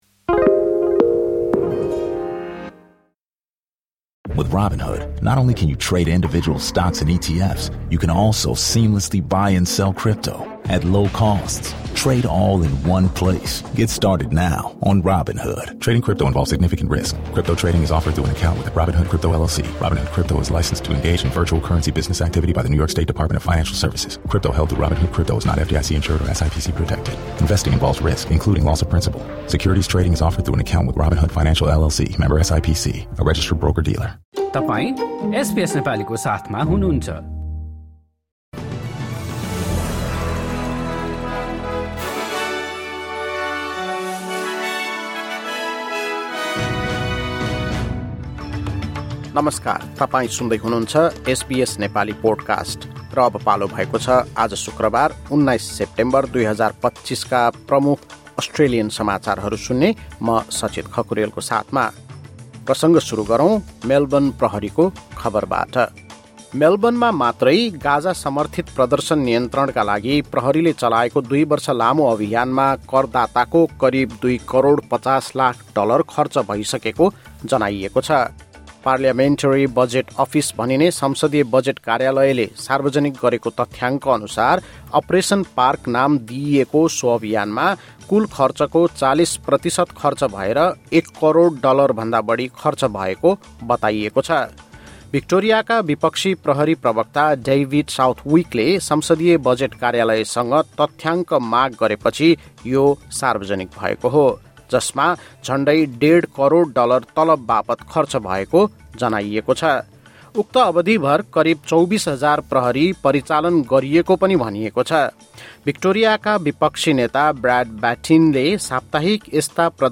SBS Nepali Australian News Headlines: Friday, 19 September 2025